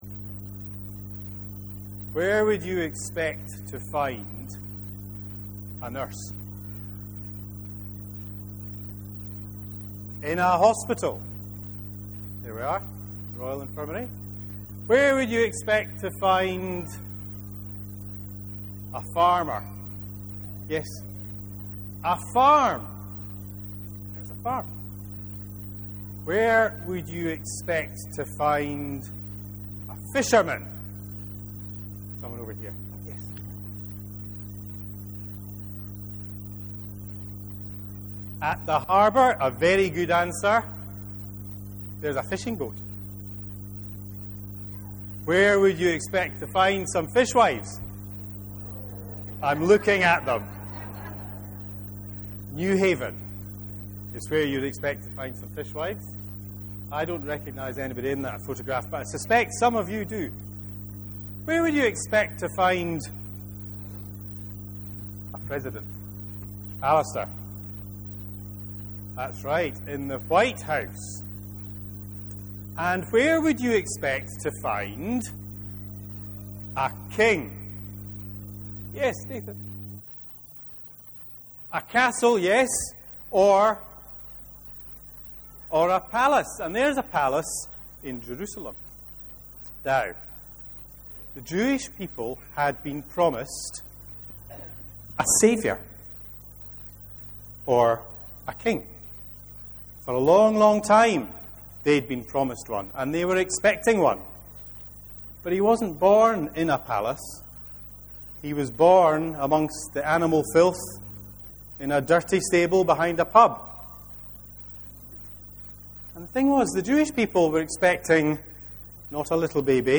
23/12/12 Sermon – Christmas service. Jesus’ humble beginnings